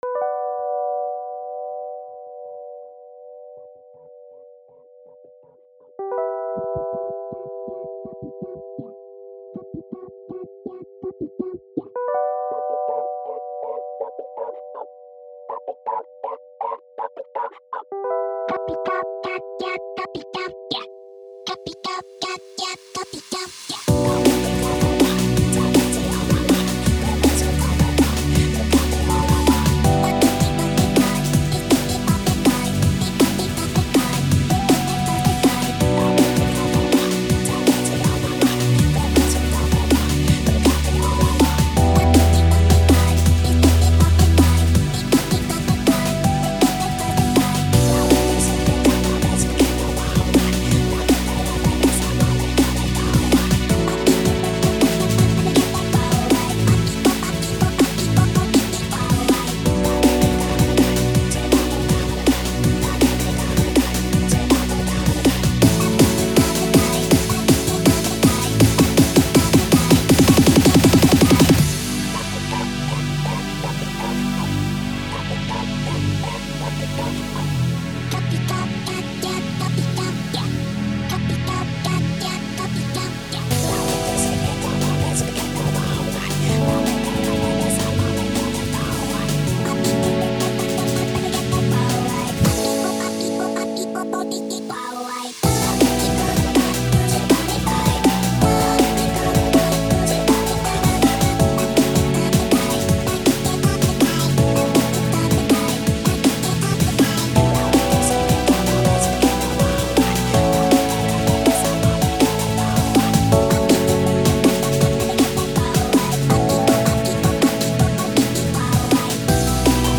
Now remastered and cleaner :D!!!
Genre: Lo-fi Drum and Bass
I like how this is a more chill take on the song
Drum N Bass